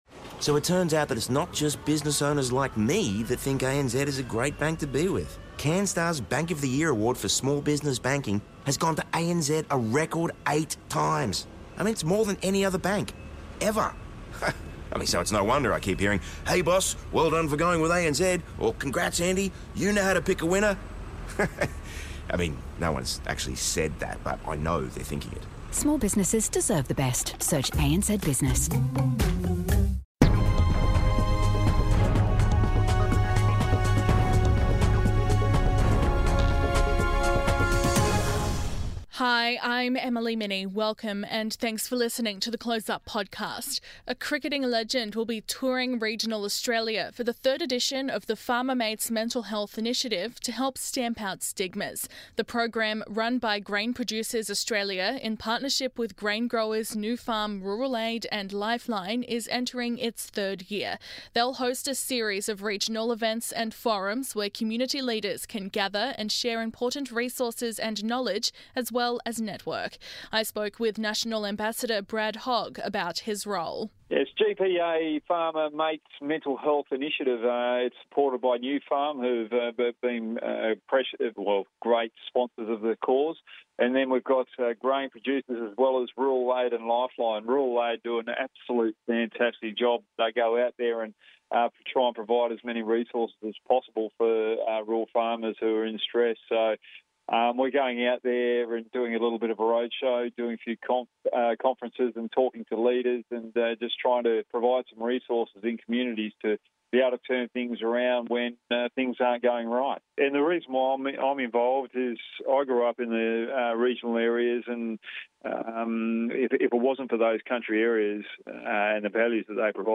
Program ambassador Brad Hogg spoke to 4BC about his role and what he was looking forward to as he prepared to kick off the 2024 program.